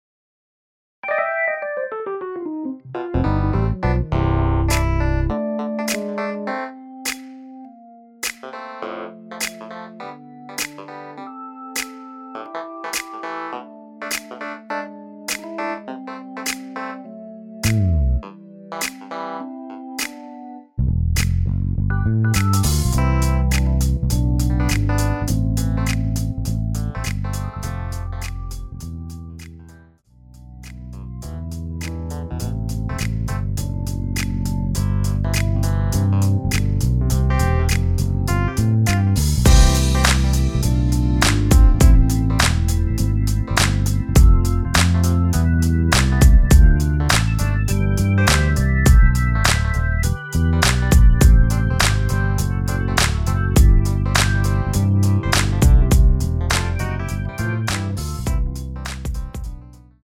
MR은 2번만 하고 노래 하기 편하게 엔딩을 만들었습니다.(본문의 가사와 코러스 MR 미리듣기 확인)
앞부분30초, 뒷부분30초씩 편집해서 올려 드리고 있습니다.